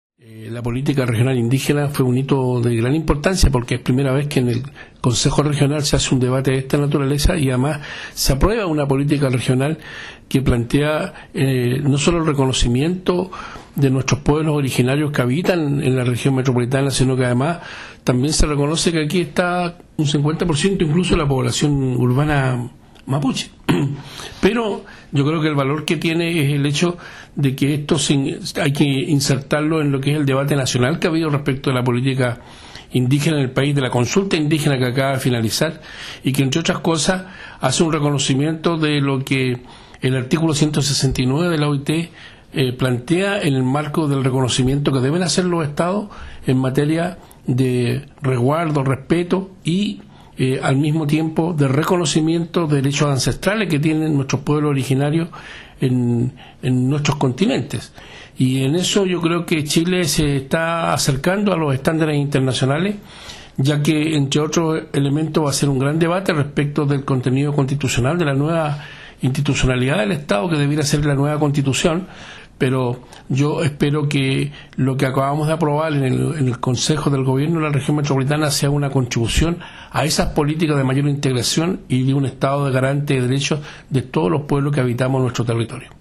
Manuel-Hernández-Presidente-del-CORE-Santiago.mp3